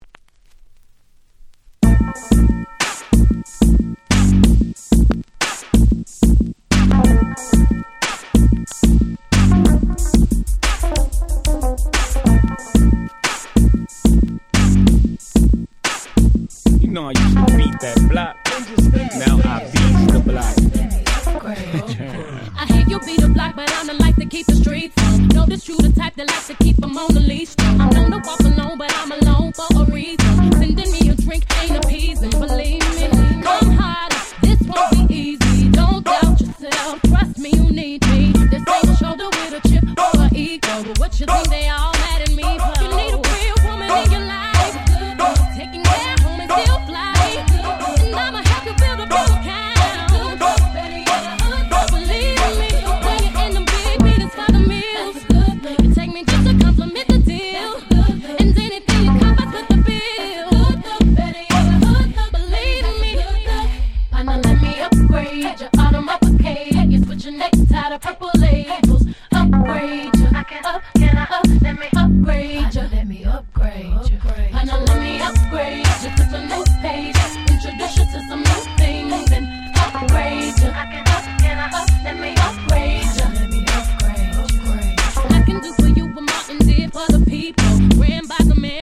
06' Smash Hit R&B !!